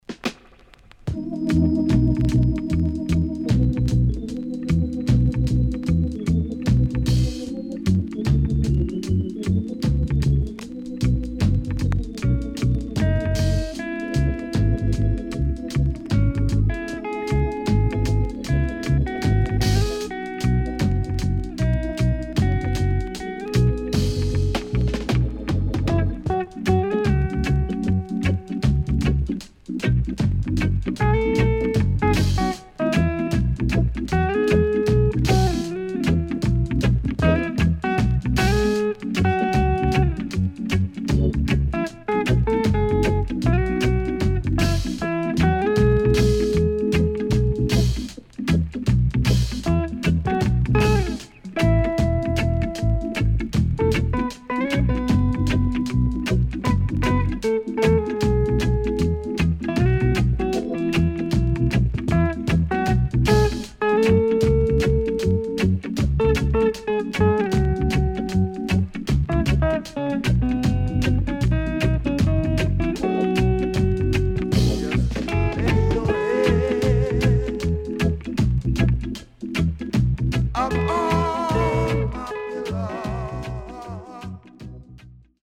CONDITION SIDE A:VG(OK)〜VG+
SIDE A:うすいこまかい傷ありますがノイズあまり目立ちません。